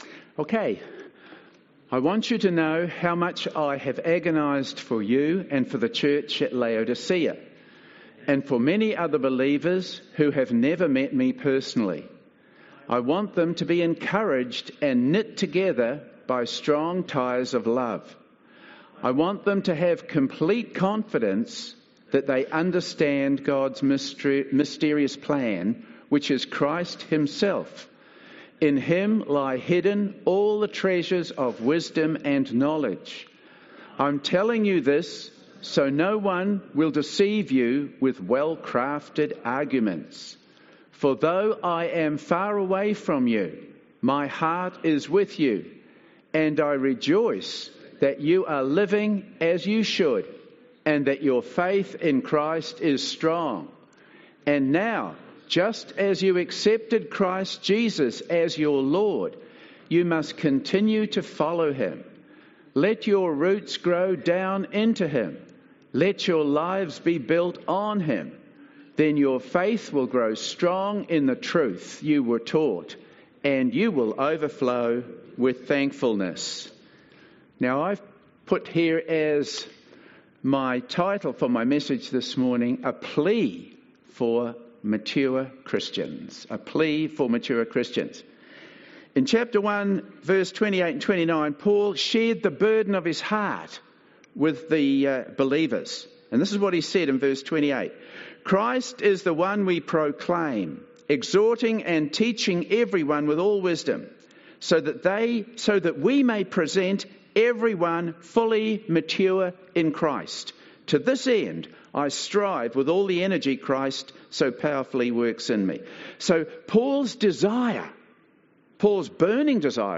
A Plea For Mature Christians, Colossians 2:1-7 - Fairfield Baptist Church